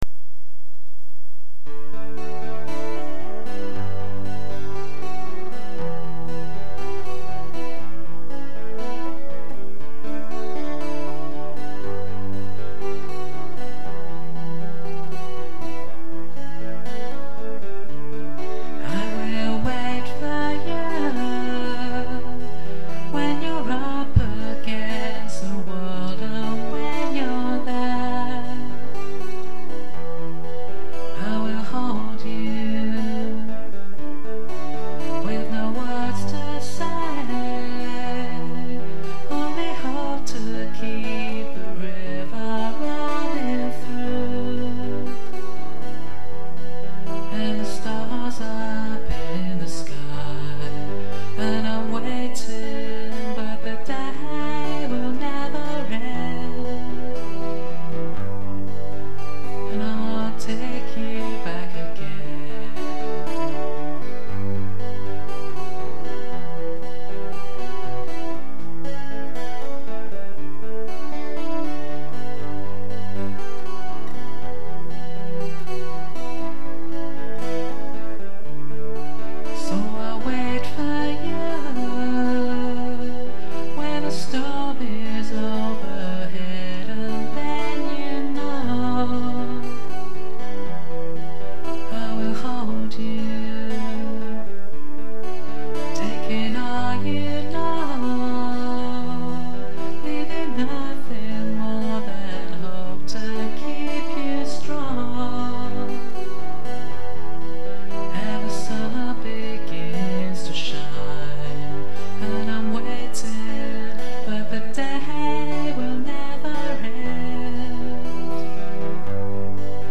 These are all lo-fi recordings made with a guitar, a microphone, and a lot of unfounded optimism.